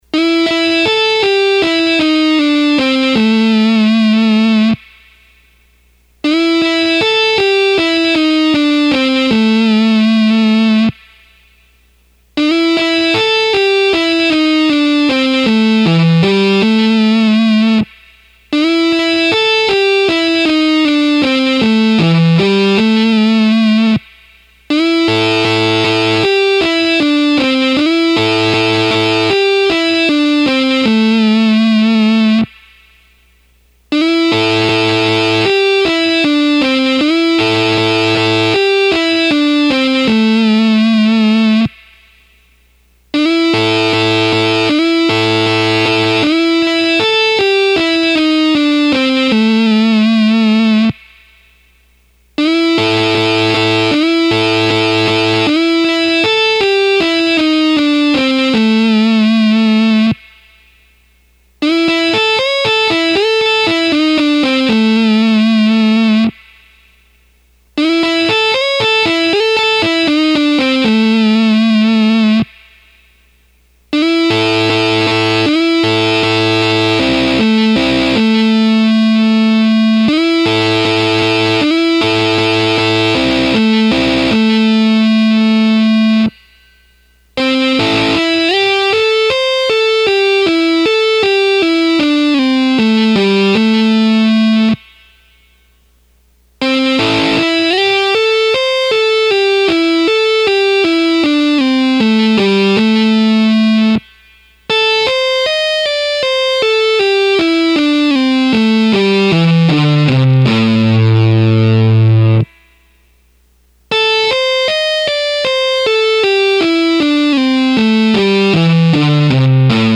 Here are some licks that every guitar player should know. These are considered to be "In The Box" and have been the basis of countless great solo's using double stops and the basic minor blues scale.
Rock_&_Blues_Licks_1_Slow.mp3